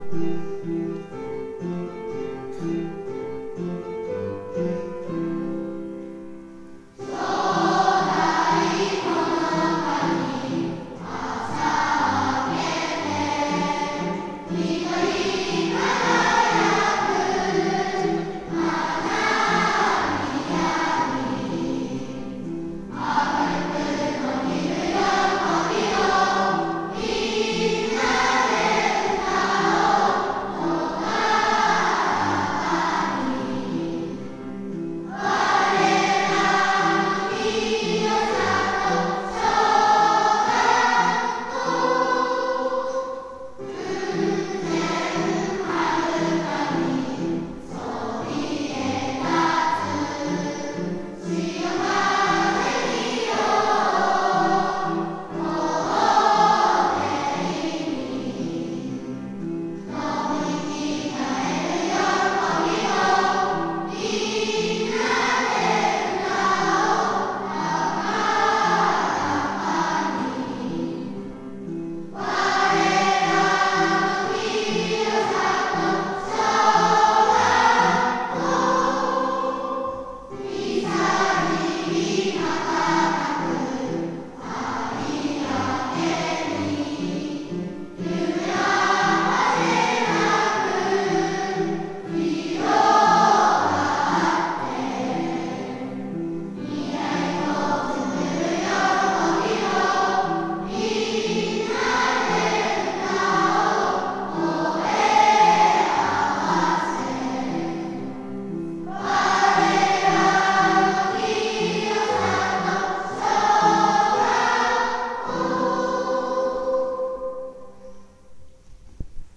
アイコン　　歌　歌声を聴くことができます⇒
校歌.m4a